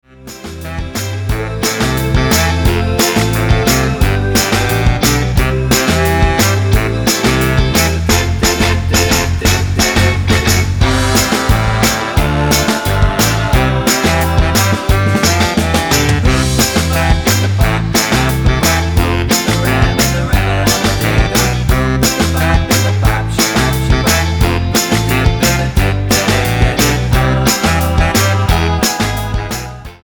--> MP3 Demo abspielen...
Tonart:B-C mit Chor